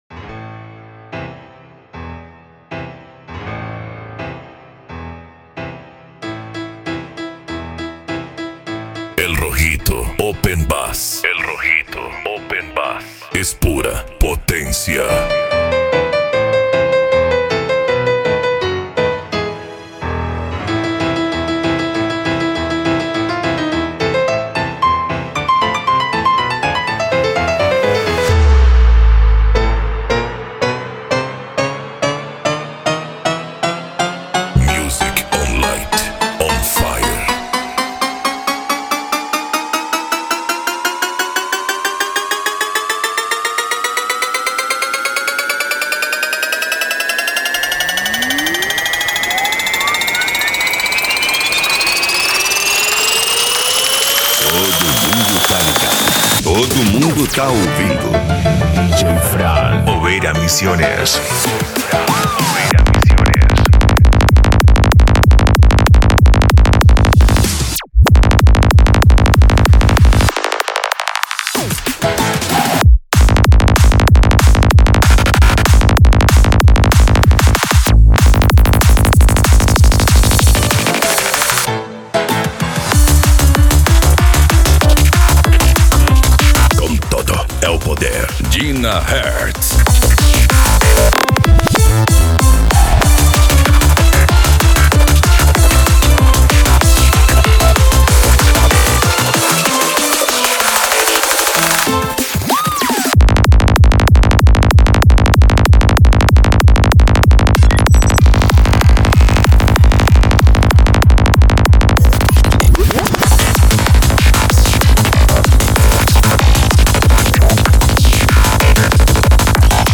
Electro House
Psy Trance
Remix